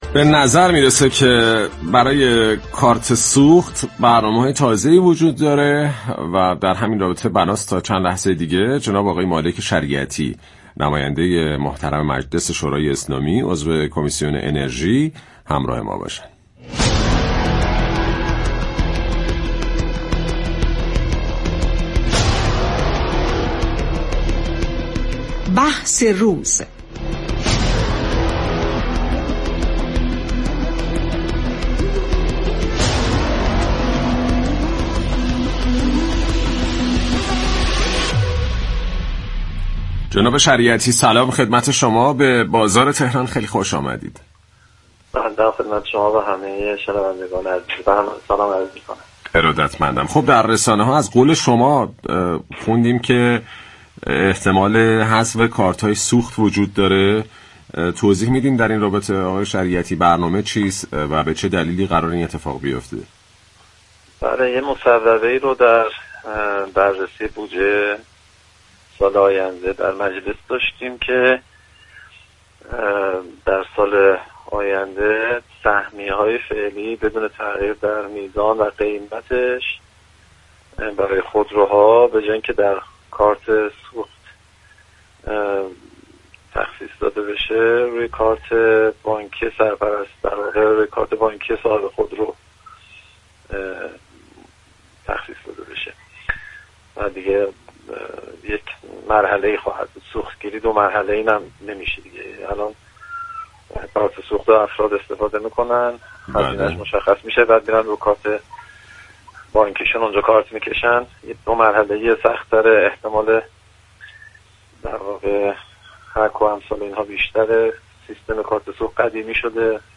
مالك شریعتی عضو كمیسیون انرژی مجلس شورای اسلامی در گفت و گو با «بازار تهران» درباره حذف كارت‌های سوخت اظهار داشت